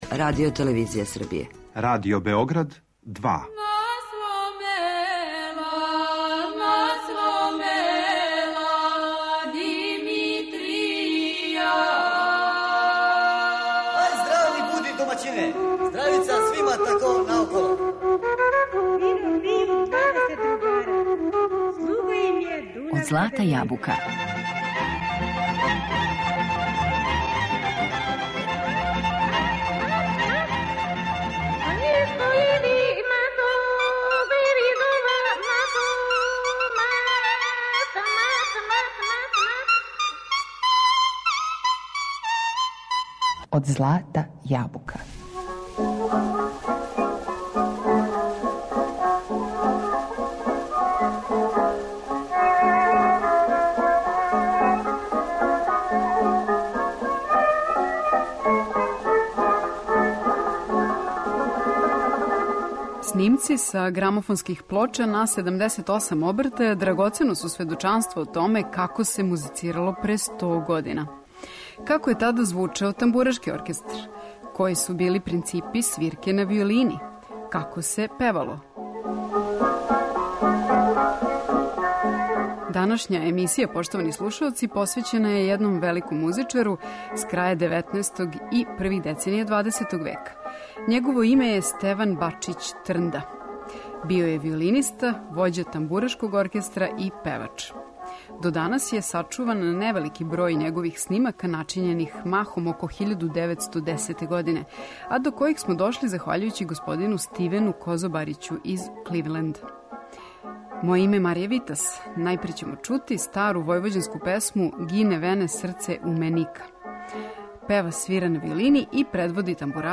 Снимци са грамофонских плоча на 78 обртаја сведоче о томе како се музицирало пре сто година, како је тада звучао тамбурашки оркестар, који су били принципи свирке на виолини, како се певало...